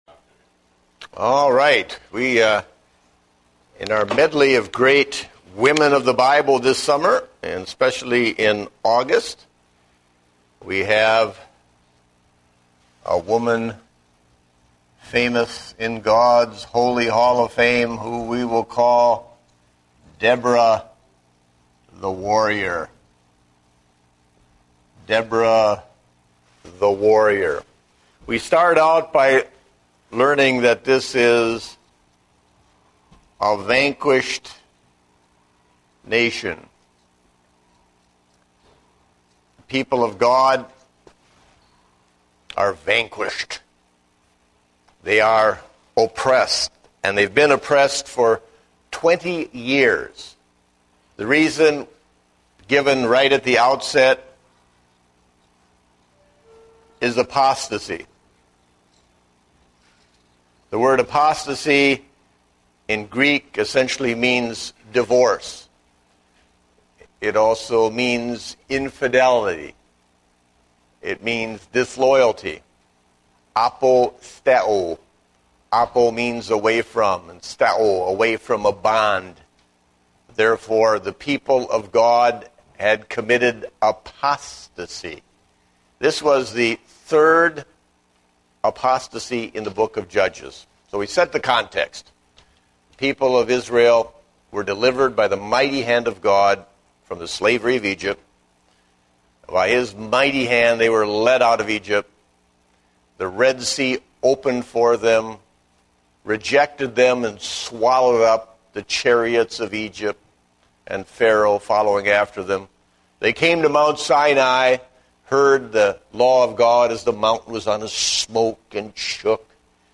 Date: August 1, 2010 (Adult Sunday School)